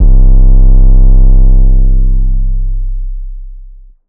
Metro 808's.wav